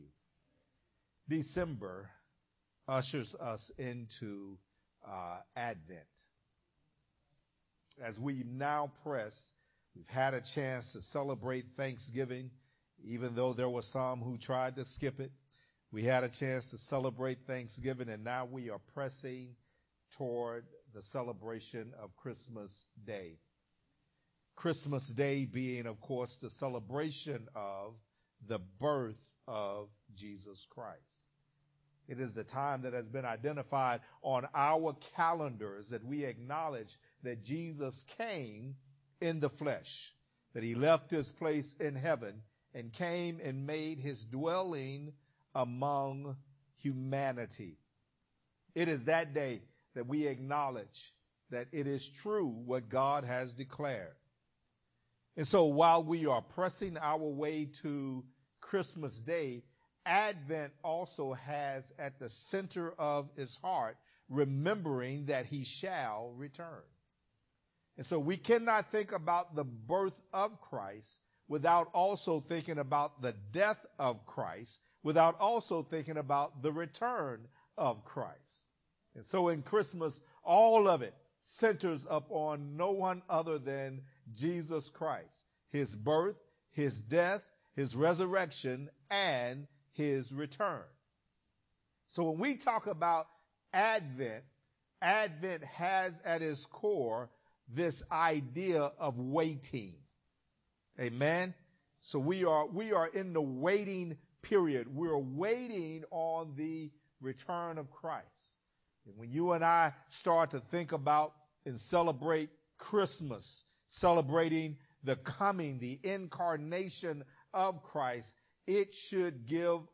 Sermons | New Joy Fellowship Ministry